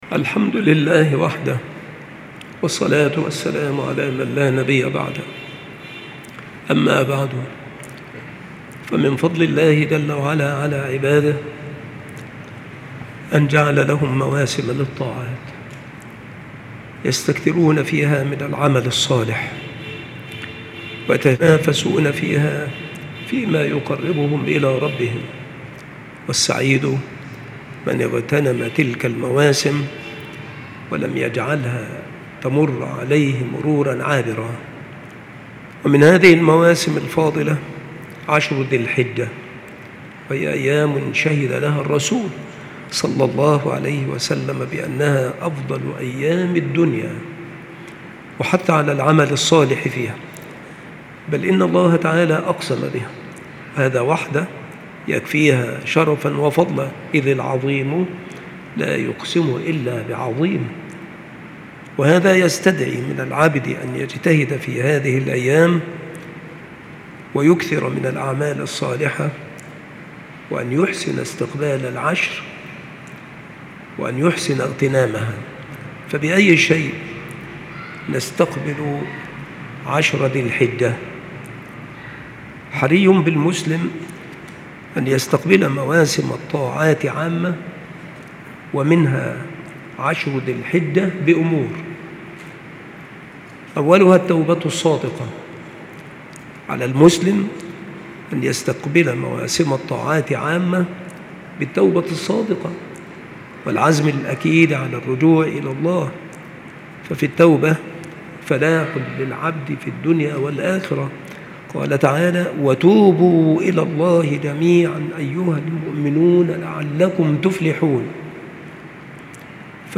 مكان إلقاء هذه المحاضرة بالمسجد الشرقي - سبك الأحد - أشمون - محافظة المنوفية - مصر